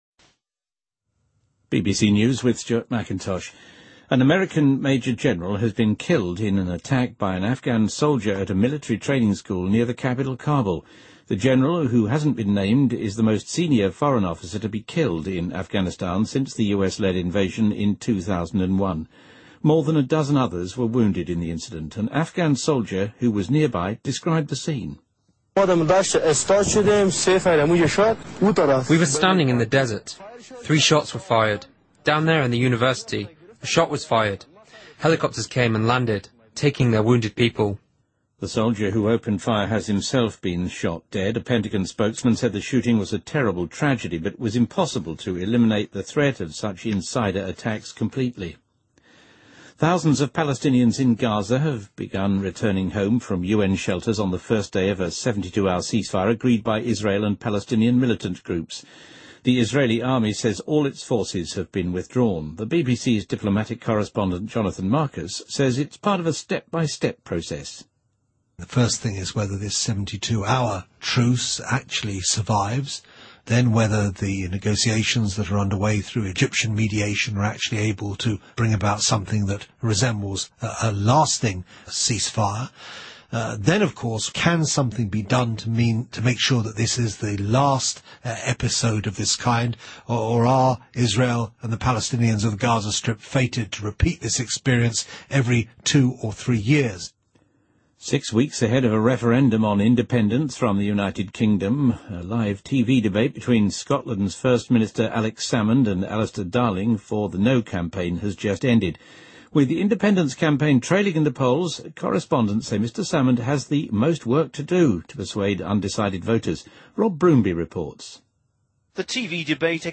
BBC news,美国二星将军在阿富汗袭击事件中遭枪杀